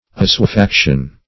Assuefaction \As`sue*fac"tion\, n. [L. assuefacere to accustom